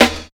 108 SNR 2 -R.wav